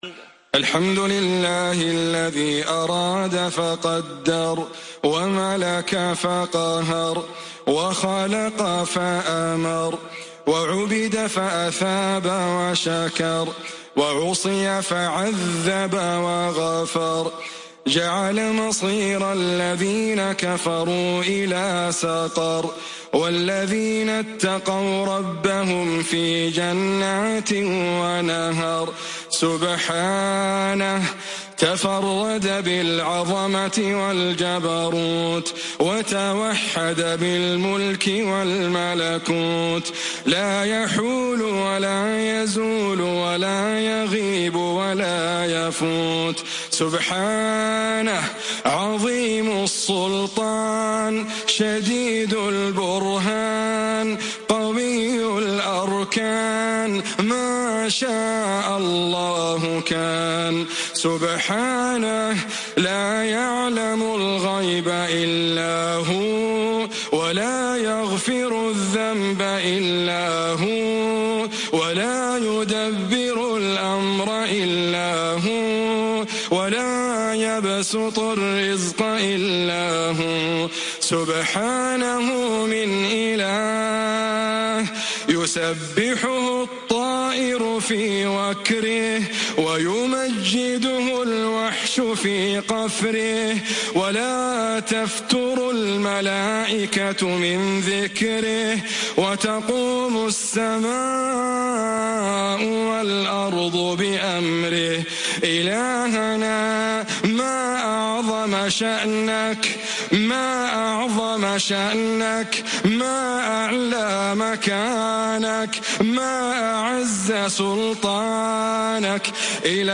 أجمل دعاء للشيخ ياسر الدوسري